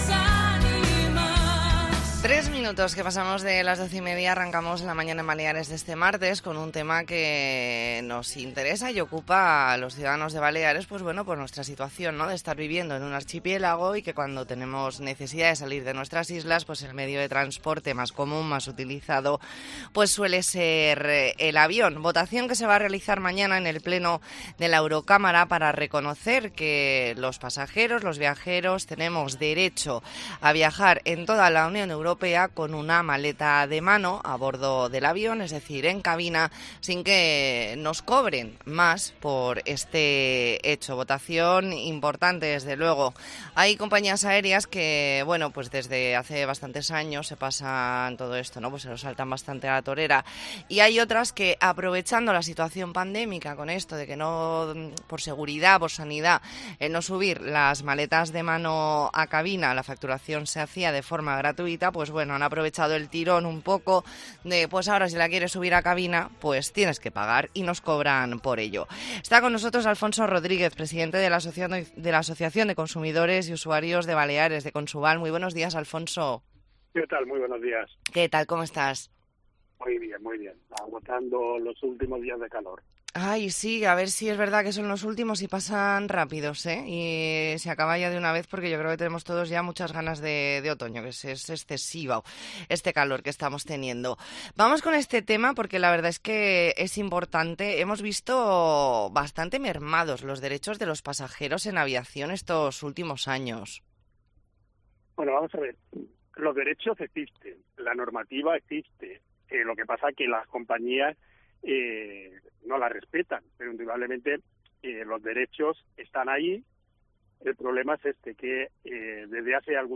Entrevista en La Mañana en COPE Más Mallorca, martes 3 de octubre de 2023.